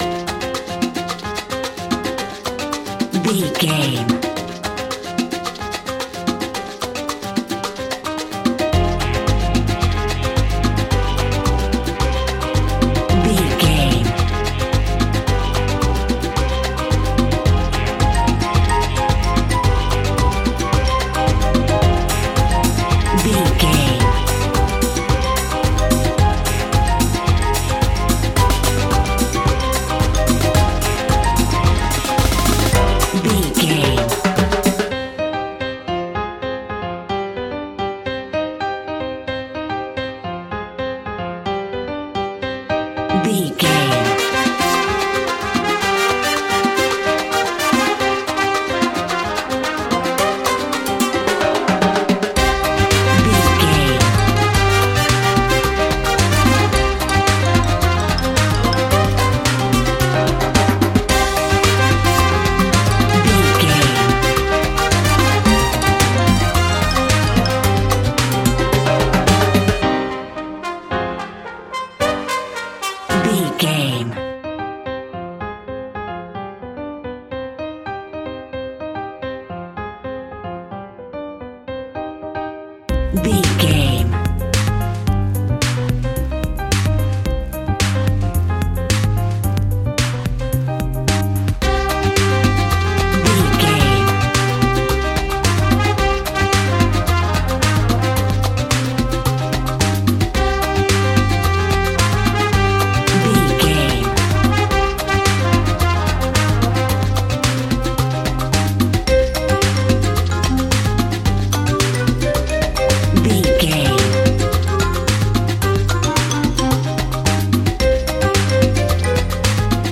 Aeolian/Minor
percussion
piano
synthesiser
horns